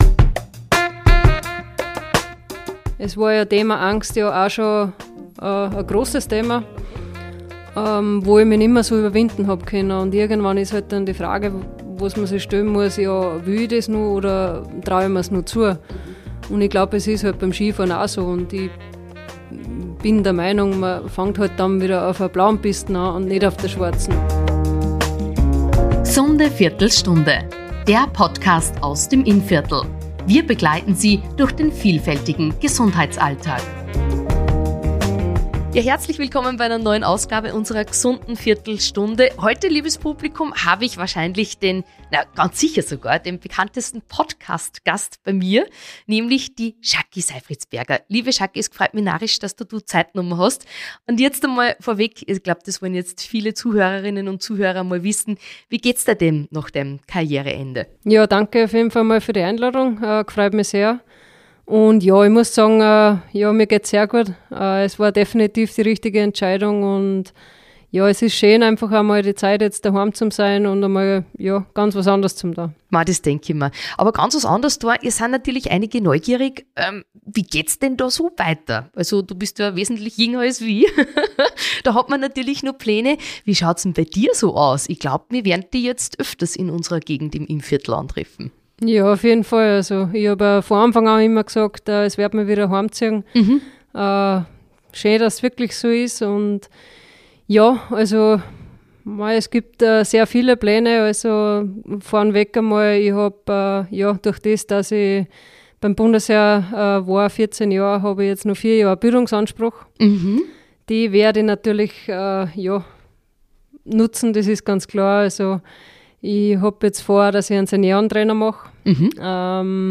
Beschreibung vor 3 Monaten Ex-Skispringerin Jaci Seifriedsberger ist zu Gast bei der „G’sunden Viertelstunde“. Sie blickt auf ihre Karriere mit 228 Weltcupstarts, drei Weltcupsiegen und sieben WM-Medaillen zurück. Des weiteren gibt die 34-jährige wertvolle Tipps zu Vorbereitung, langsamem Start und realistischen Vorsätzen rund um den Wintersport.